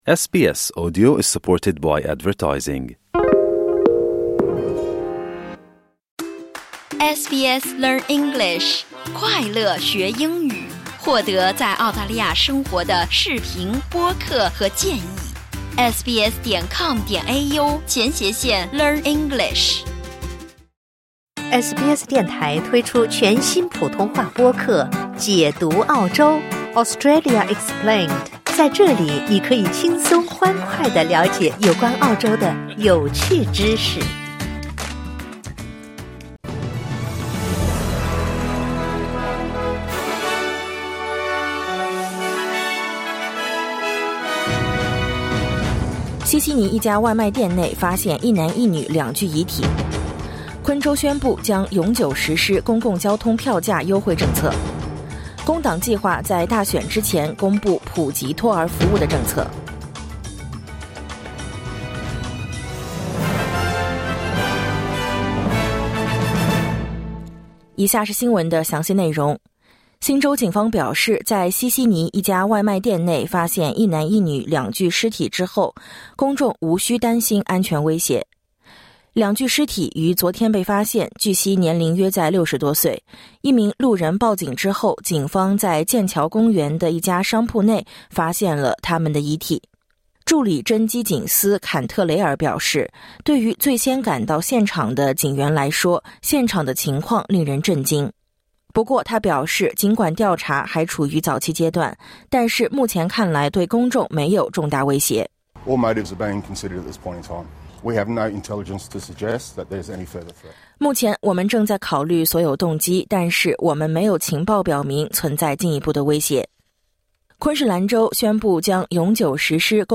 SBS早新闻（2024年12月1日）